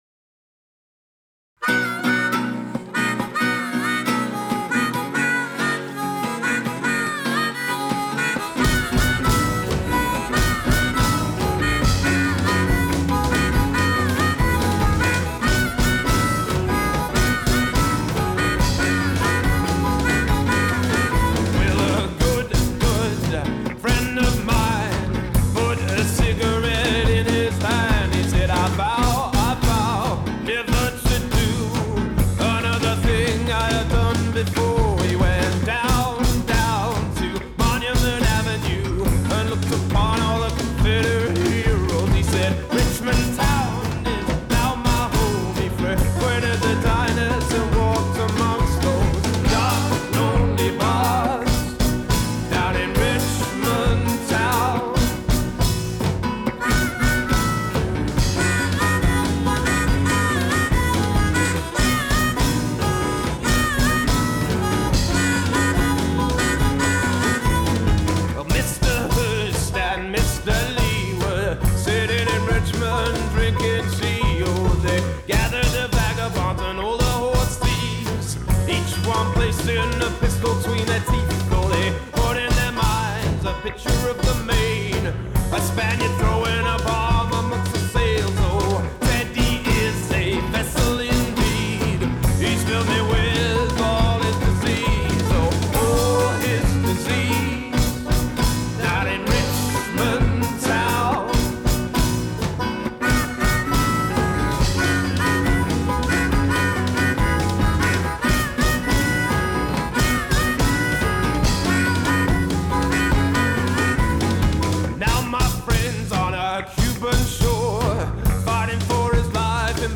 classic country with added alt-country